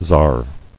[tsar]